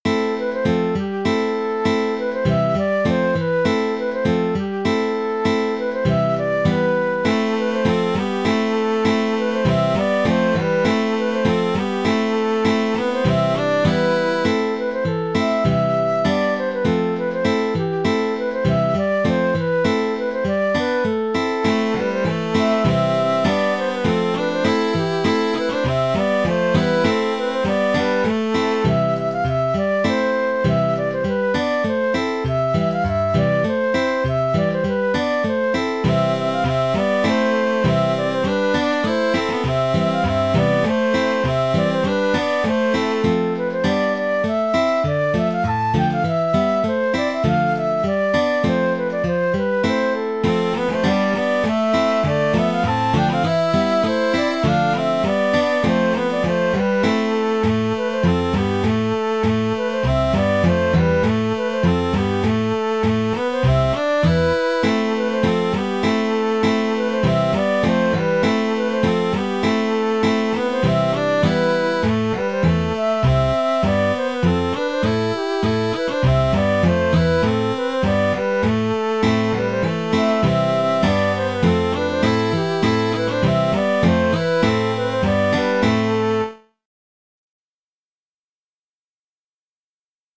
Musique bretonne
Hanter dro
Excusez la mauvaise qualité du son du mp3 assez ancien.